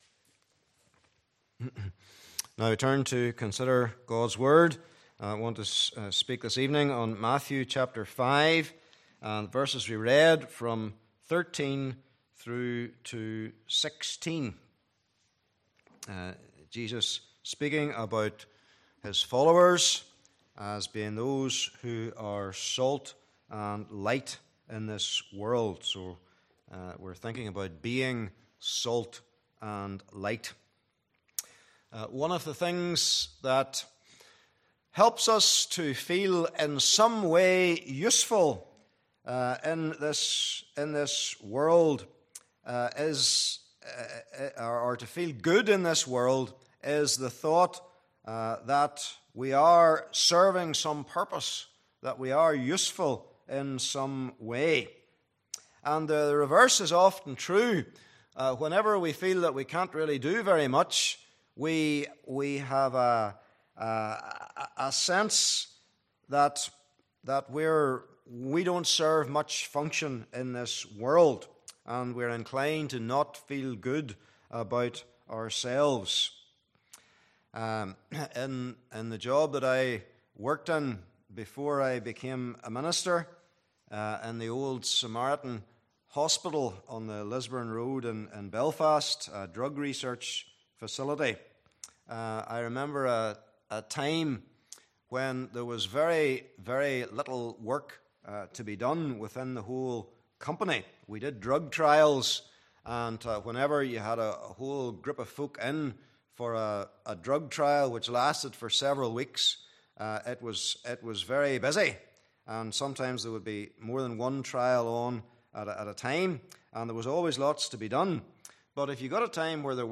Series: Sermon on the Mount
Service Type: Evening Service